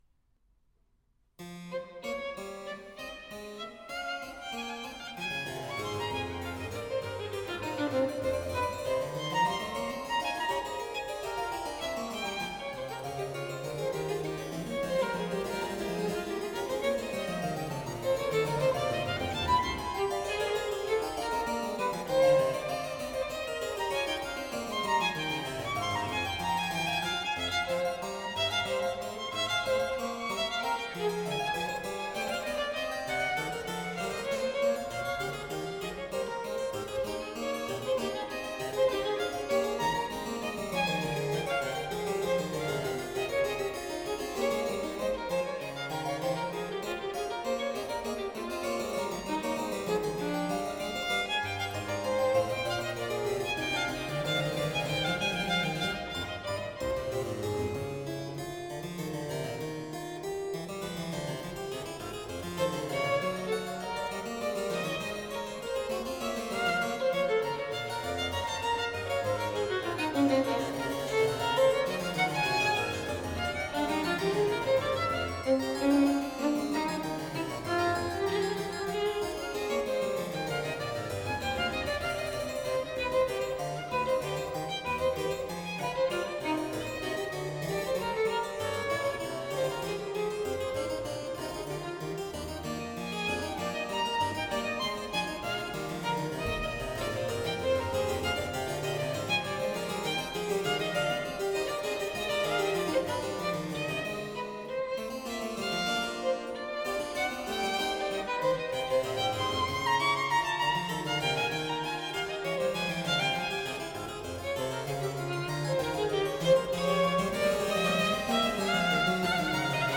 Johann Sebastian Bach: Vivace from Sonata V, BWV 1018. Postludium. (Impro)